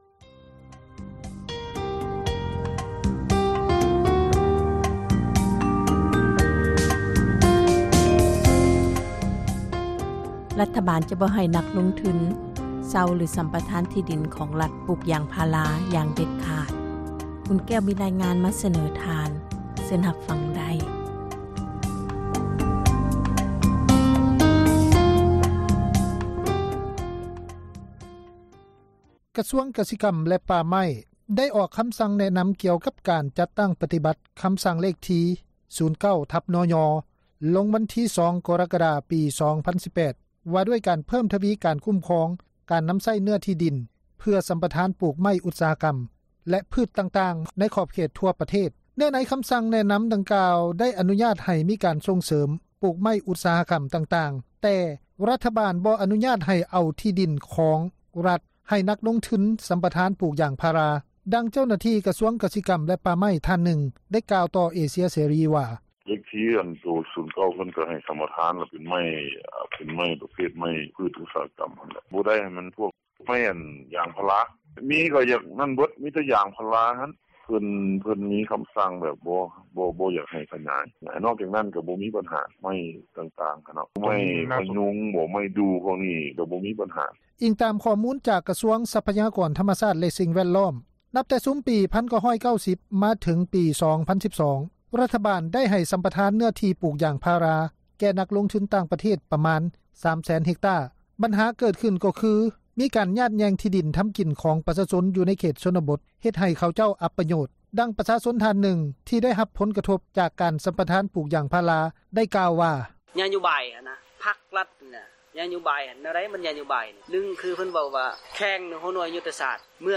ເຈົ້າໜ້າທີ່ກະຊວງກະສິກັມແລະປ່າໄມ້ ທ່ານນຶ່ງໄດ້ກ່າວຕໍ່ ເອເຊັຍເສຣີ ວ່າ:
ປະຊາຊົນທ່ານນຶ່ງ ທີ່ໄດ້ຮັບຜົນກະທົບຈາກການສຳປະທານປູກຢາງພາຣາ ໄດ້ກ່າວຕໍ່ເອເຊັຍເສຣີ ວ່າ: